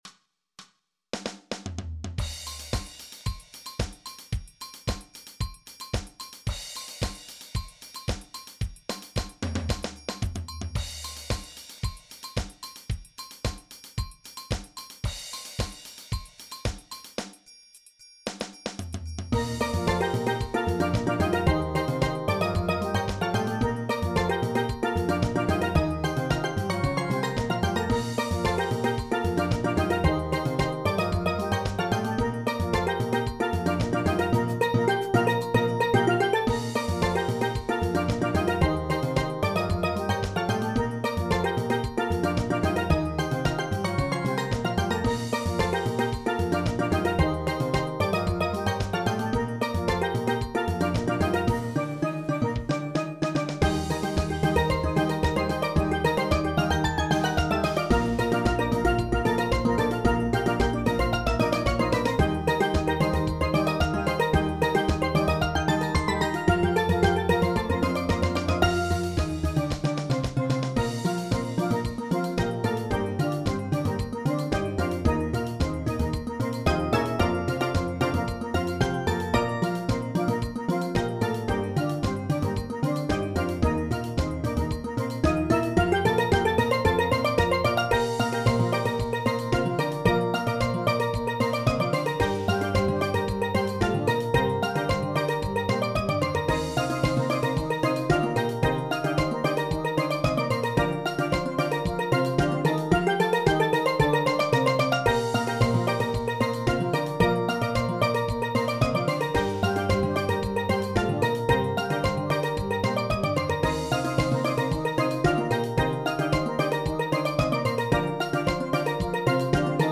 Genre: Steel Band
Guitar/Cello
Drum Set
Engine Room 1: Shaker (or Güira/Metal Scraper)